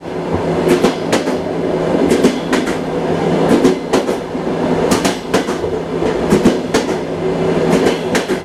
new_rus_train_sound.ogg